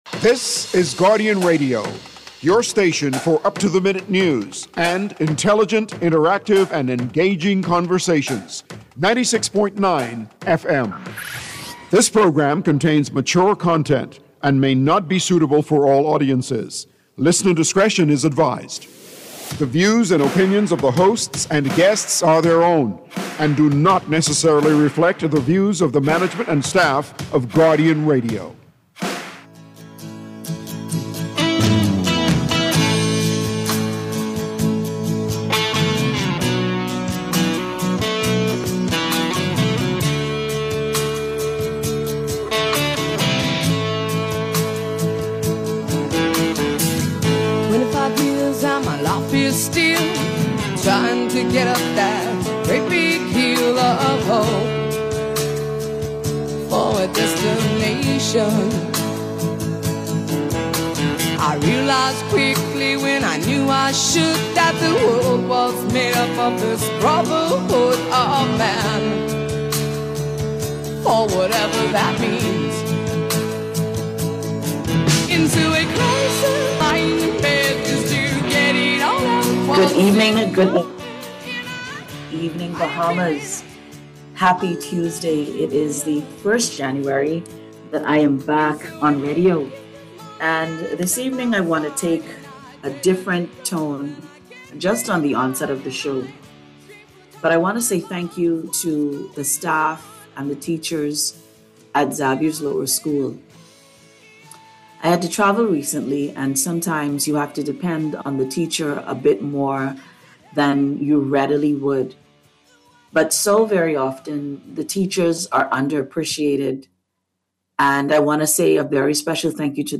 Radio Talk Show